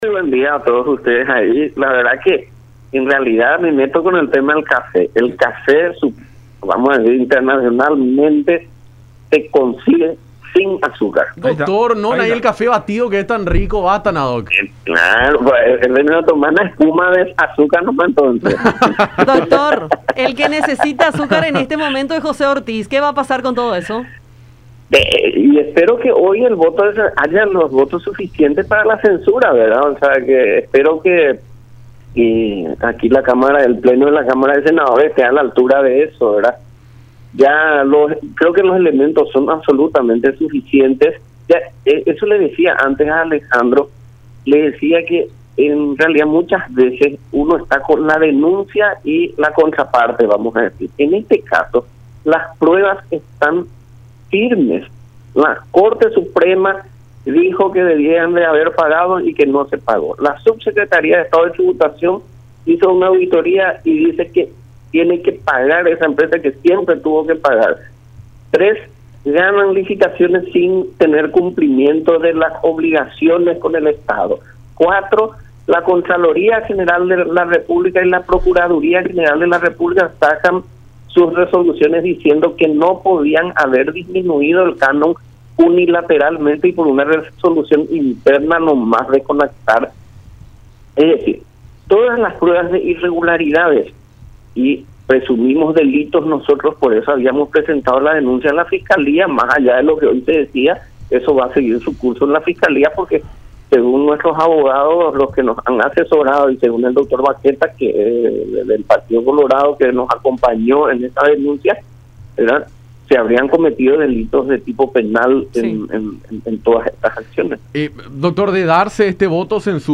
Más allá de lo que presentamos en Fiscalía y que seguirá su curso, el tema es poder conseguir el voto censura”, dijo Querey en diálogo con Enfoque 800 a través de La Unión.